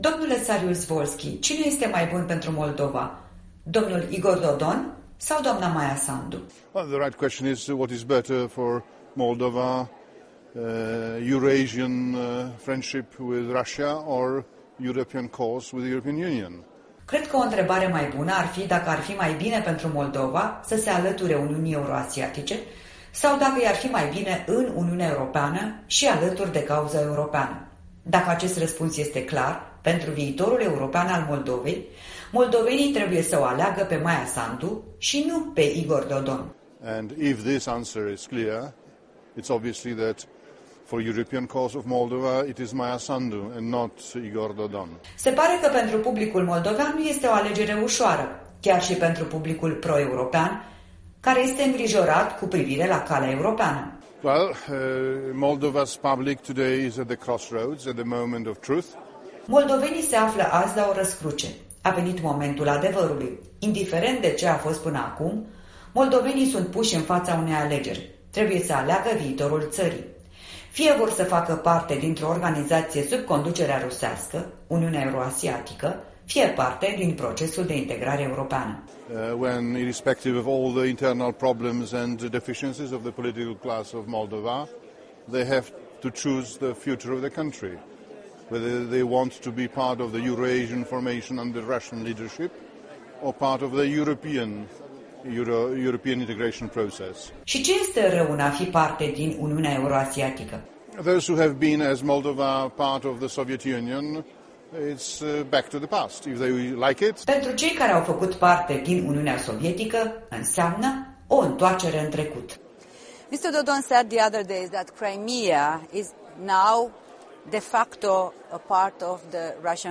Un interviu la Strasbourg cu vicepreședintele grupului Partidului Popular European.
În direct de la Strasbourg cu europarlamentarul Jacek Saryusz-Wolski